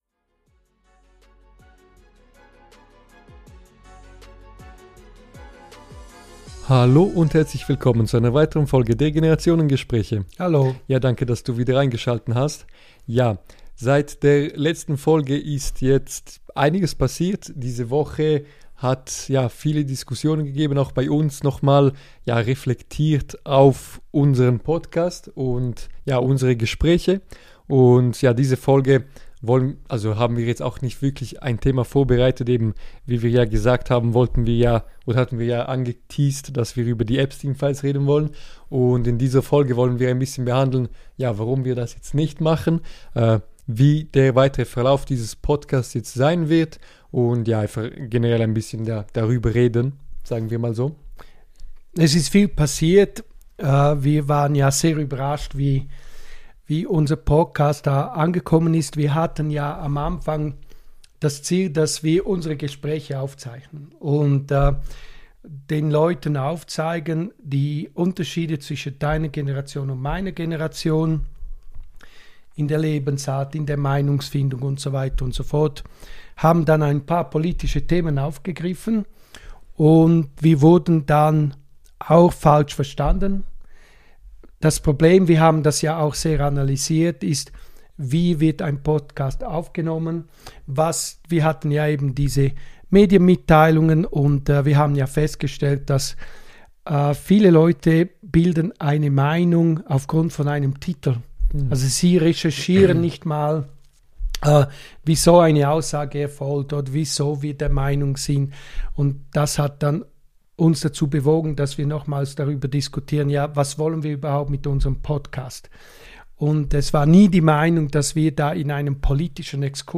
Danach reden wir über die Fastenzeit und darüber, was es bedeutet, bewusst auf Dinge zu verzichten – besonders in einer Zeit, in der wir ständig online sind. Eine ruhige, persönliche Folge über Gewohnheiten, Prioritäten und bewusste Entscheidungen im Alltag.